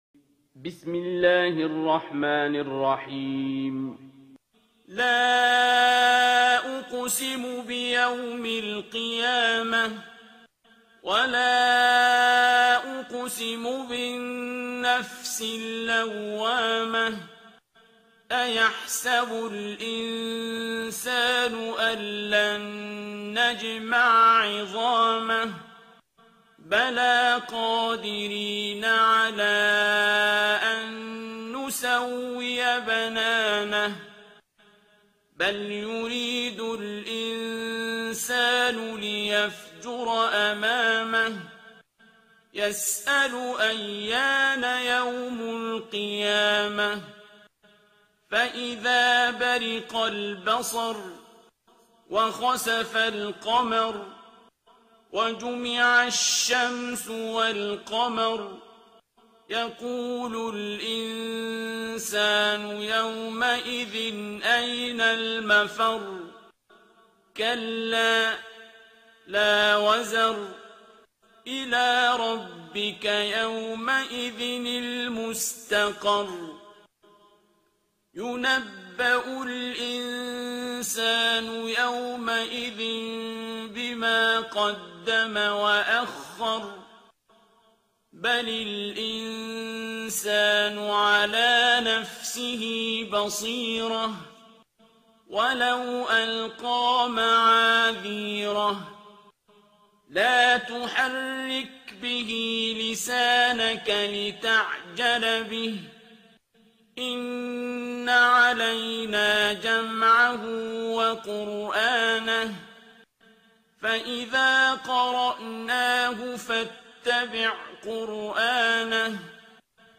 ترتیل سوره قیامه با صدای عبدالباسط عبدالصمد
075-Abdul-Basit-Surah-Al-Qiyama.mp3